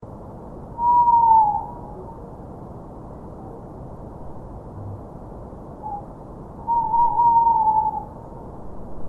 Kodukakk-suvine.ogg